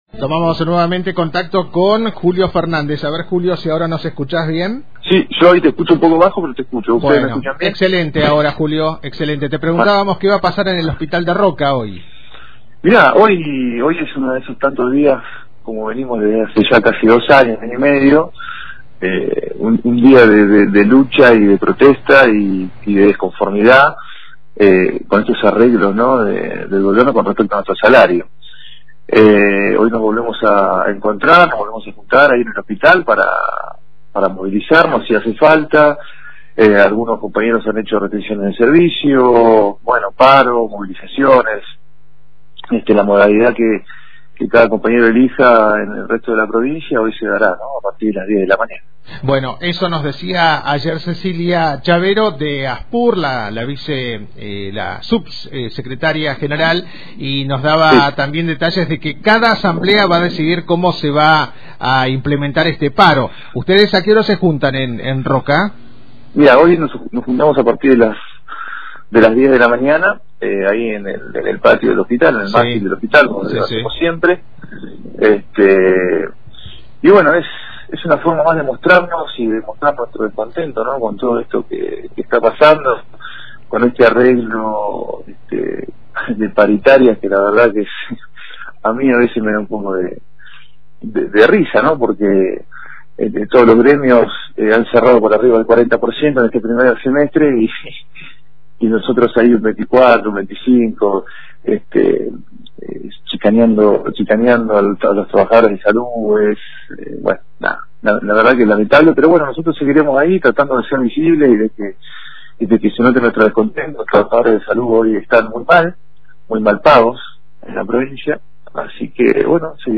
dialogó con Antena Libre y expresó cuales son las demandas y qué medidas de luchas se llevarán adelante en salud pública hoy.